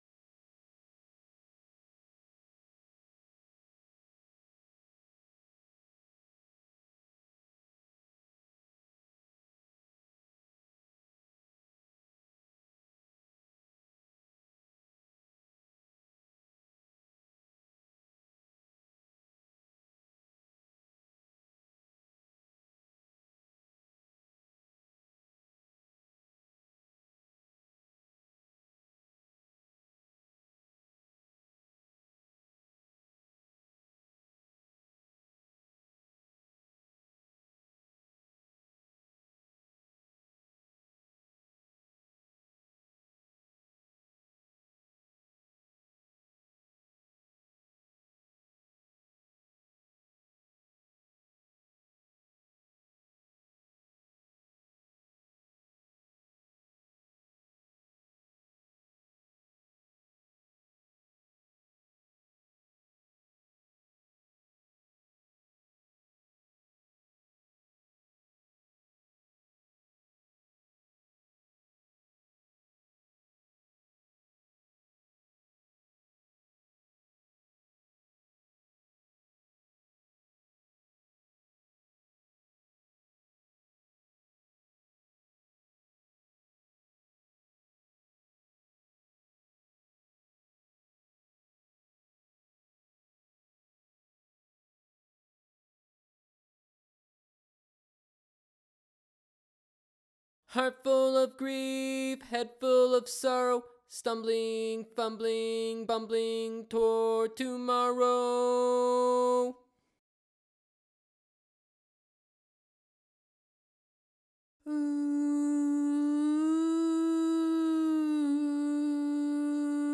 Toward Tomorrow - Vocal Chorus 2 - High.wav